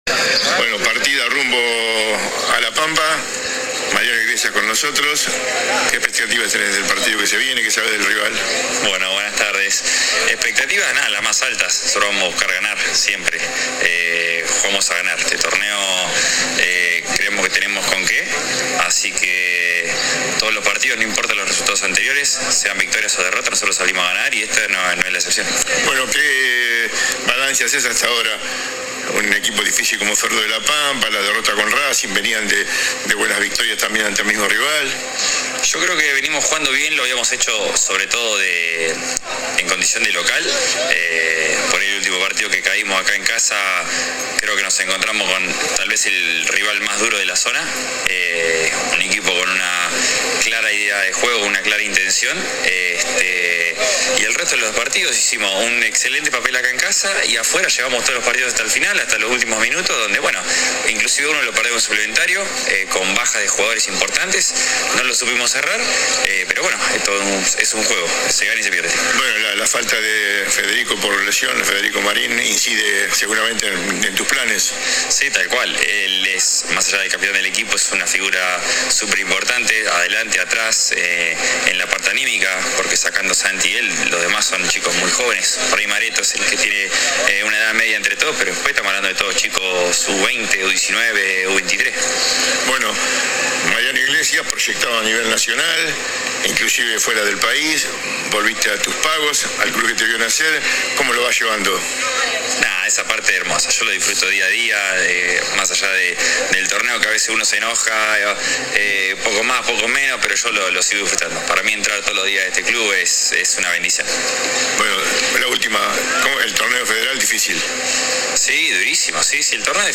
«Emblema Deportivo» estuvo en el Parque Carlos Guerrero en el momento de la salida.
AUDIO DE LA ENTREVISTA